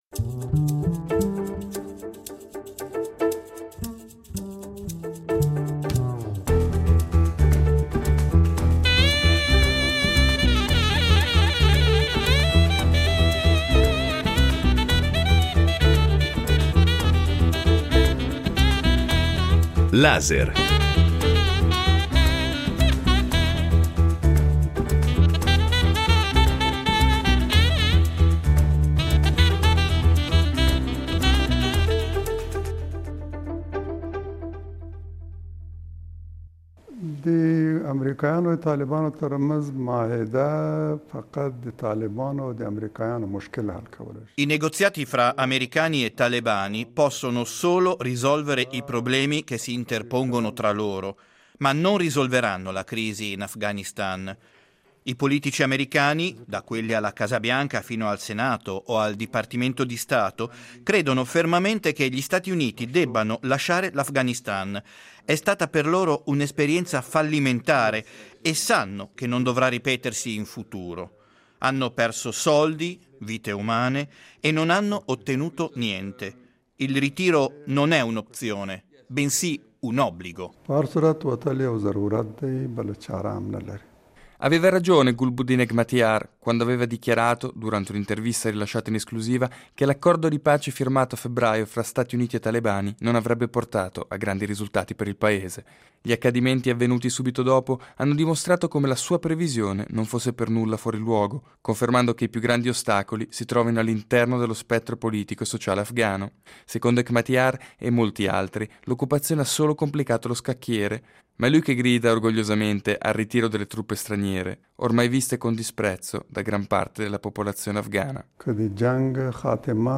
Lo abbiamo incontrato nella sua casa, per parlare di storia, elezioni e processo di pace fra Stati Uniti e Talebani.